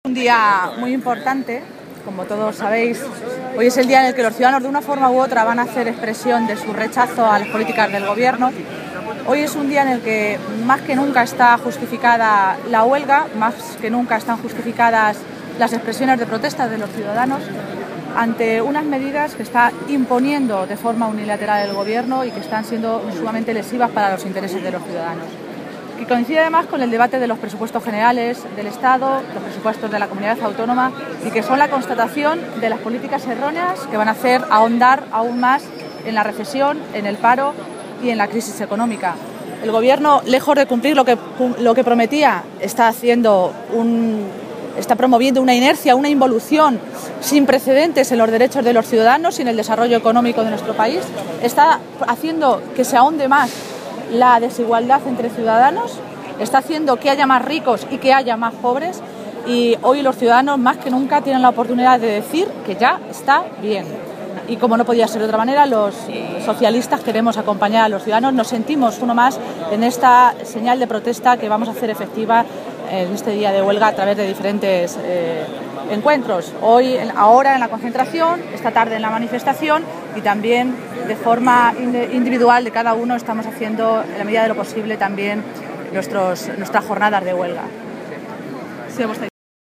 Maestre se pronunciaba de esta manera en declaraciones a los medios de comunicación en la toledana Plaza de Zocodover, donde al mediodía se ha producido una concentración de apoyo al paro general organizada por los sindicatos convocantes de la huelga.
Cortes de audio de la rueda de prensa